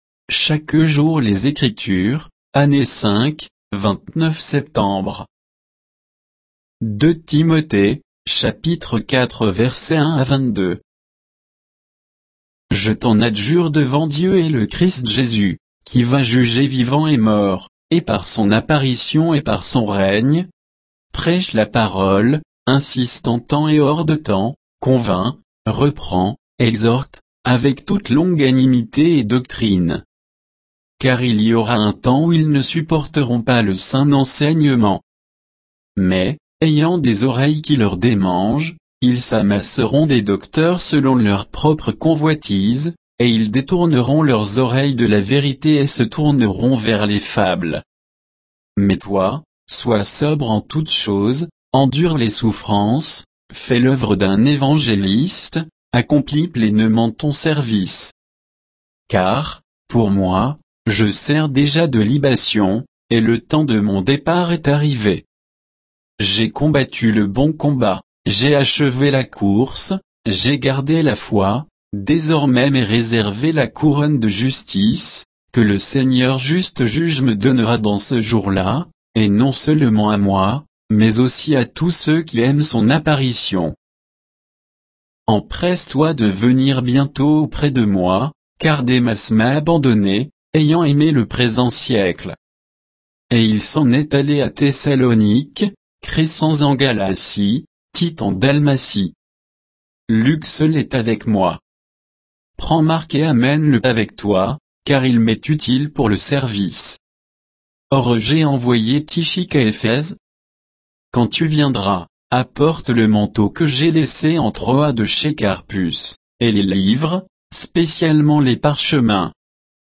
Méditation quoditienne de Chaque jour les Écritures sur 2 Timothée 4